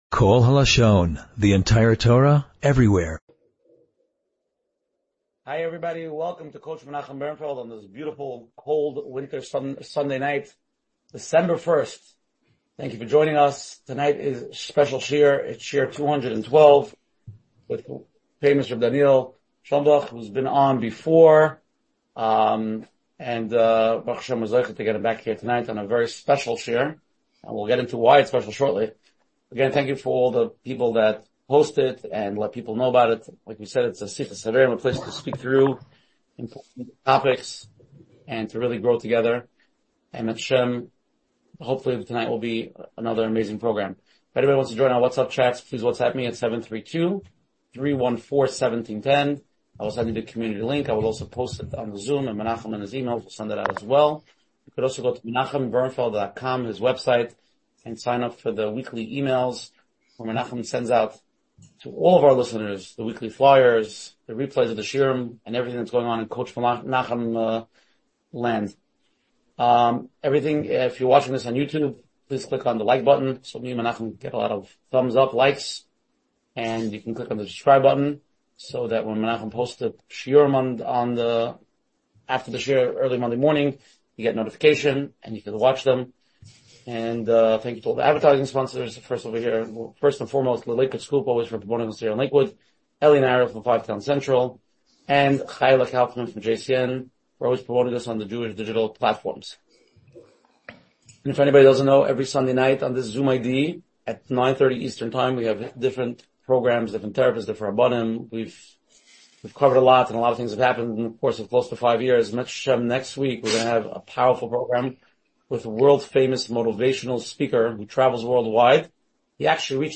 hosť : JUDr. Štefan Harabin